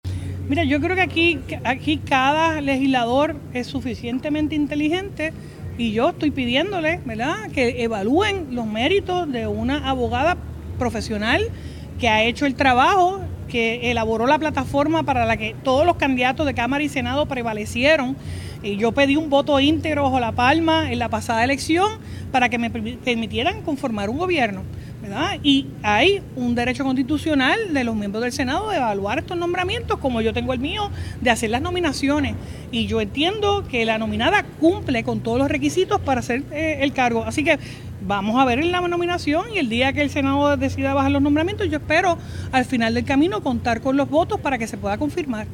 En conferencia de prensa, la primera mandataria destacó la gesta de Ferraiuoli al enfrentar la vista de unas cinco horas, y señaló cómo el pasado 30 de diciembre de 2024, cuando nominó a la secretaria, todo estaba en orden, por lo que indicó no entender por qué condenan que haya solicitado una prórroga.